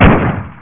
Pistol
Sound-Demo
wpistol.wav